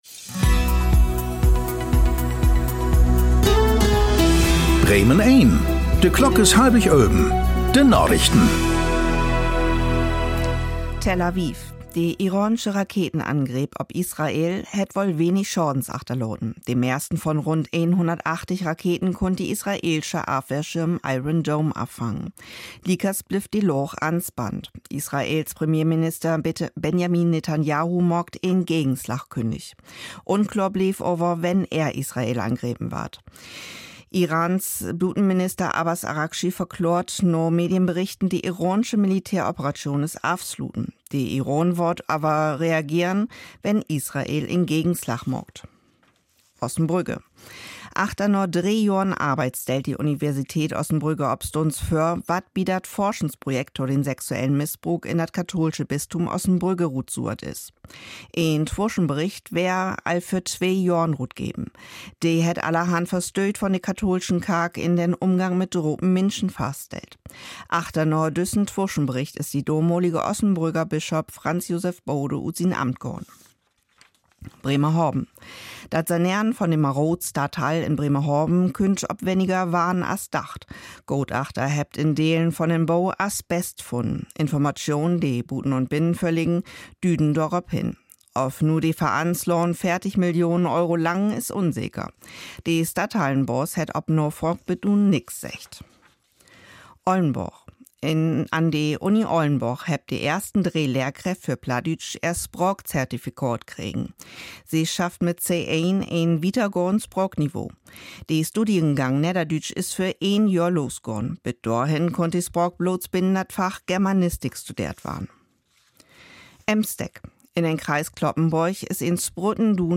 Aktuelle plattdeutsche Nachrichten werktags auf Bremen Eins und hier für Sie zum Nachhören.
… continue reading 1090 episodios # Tägliche Nachrichten # Nachrichten # Thu Apr 01 11:24:10 CEST 2021 Radio Bremen # Radio Bremen